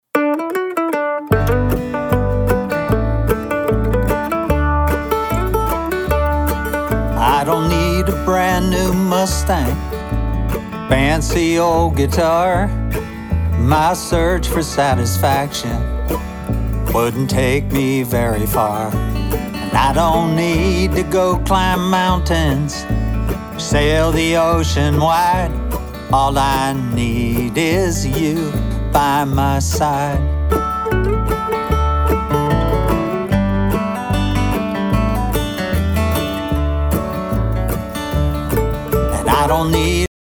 a mix using saturation
I mixed a song both ways, using saturation on the mix bus and using no saturation... and then a/b them every 5 seconds. The first 5s has no saturation, the second 5s does, etc...